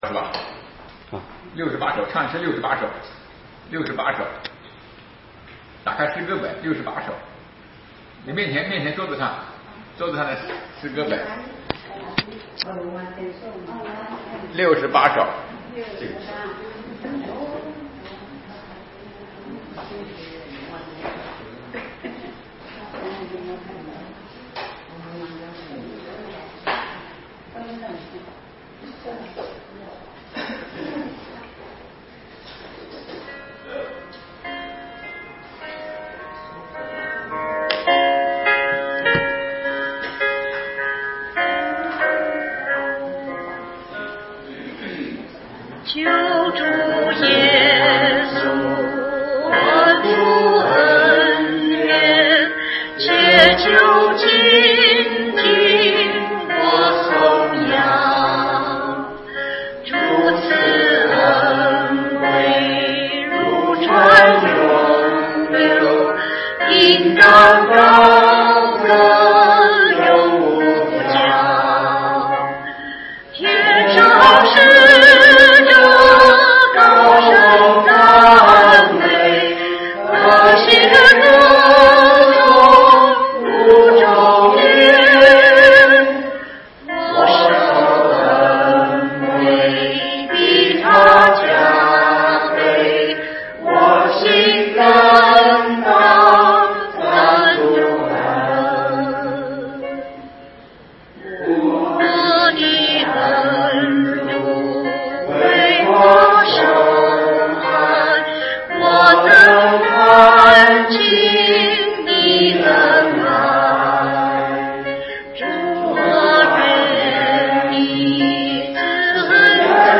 创世记第36讲 2020年7月28日 下午5:40 作者：admin 分类： 创世记小组圣经讲道 阅读(4.38K